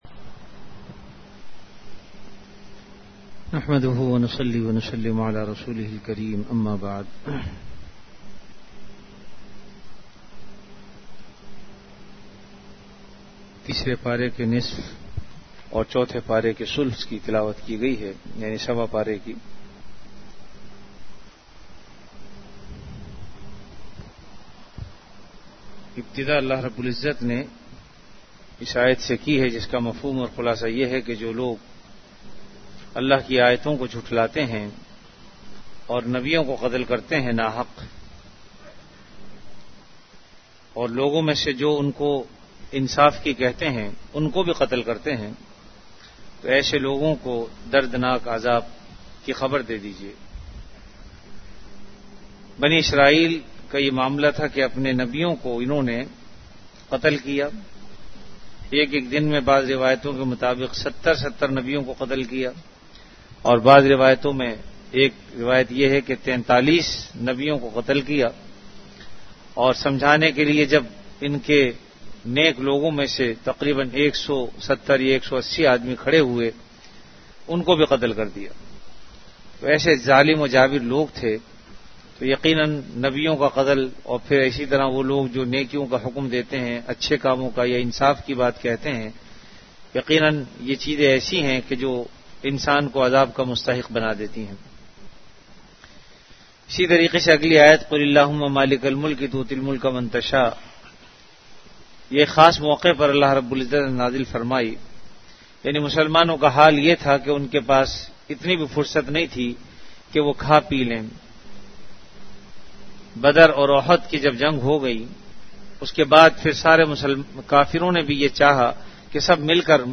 Ramadan - Taraweeh Bayan · Jamia Masjid Bait-ul-Mukkaram, Karachi